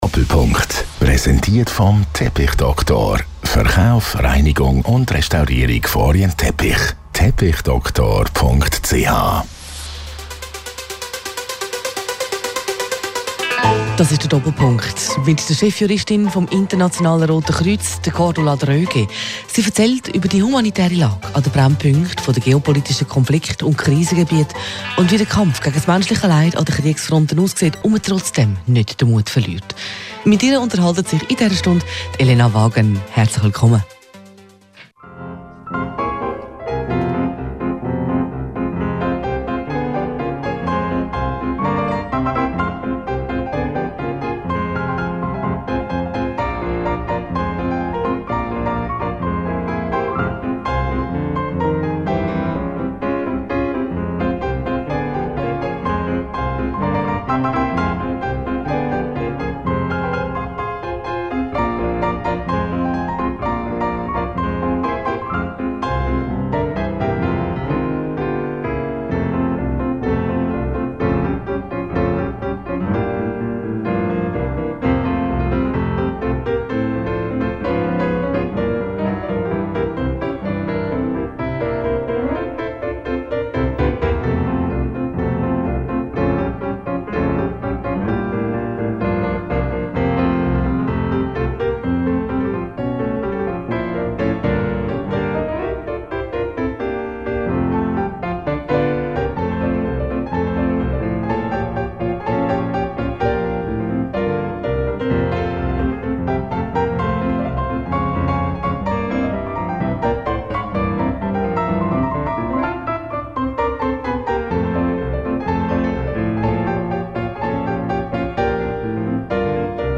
Roger Schawinski im Gespräch mit Gästen – die legendärste Talkshow der Schweiz.